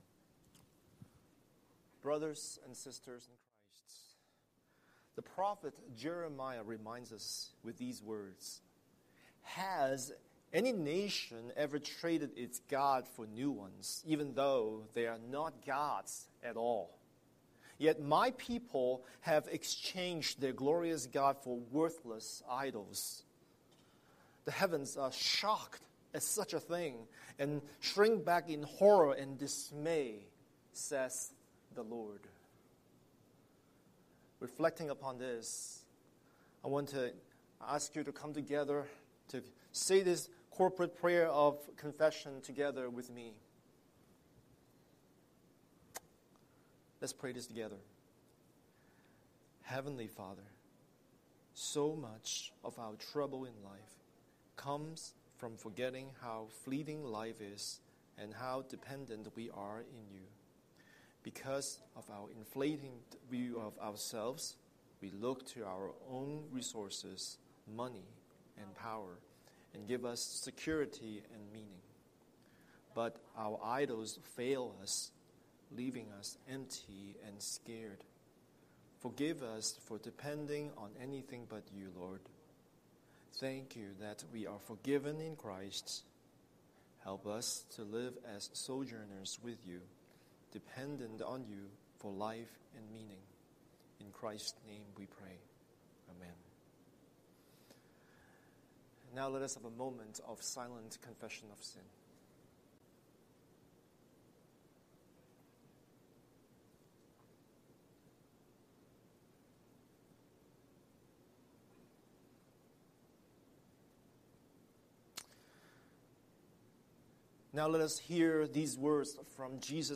Scripture: Galatians 5:13-14 Series: Sunday Sermon